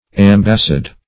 Search Result for " ambassade" : The Collaborative International Dictionary of English v.0.48: Ambassade \Am"bas*sade\, Embassade \Em"bas*sade\, n. [F. ambassade.